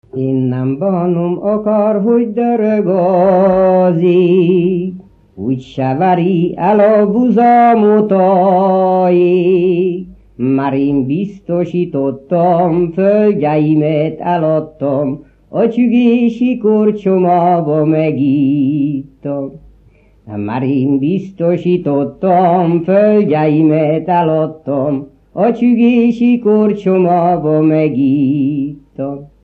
Moldva és Bukovina - Moldva - Csügés
Műfaj: Lassú csárdás
Stílus: 8. Újszerű kisambitusú dallamok
Kadencia: 2 (2) 1 1